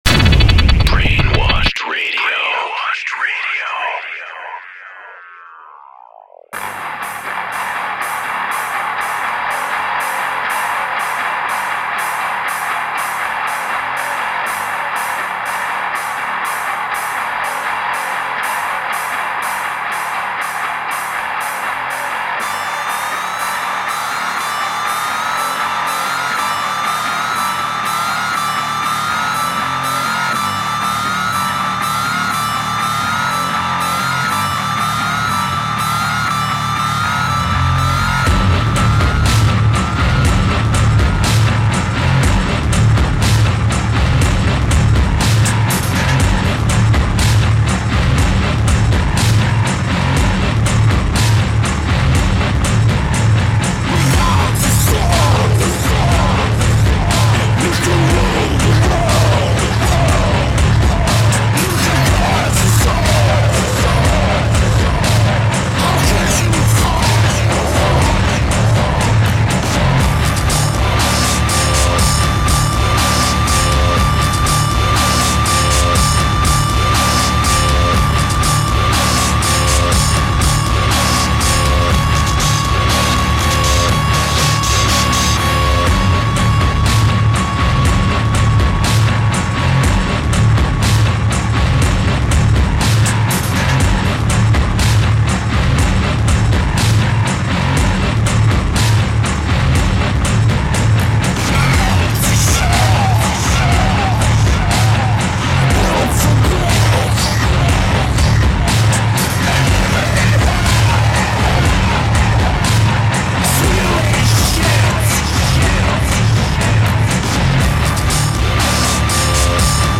Eclectic Music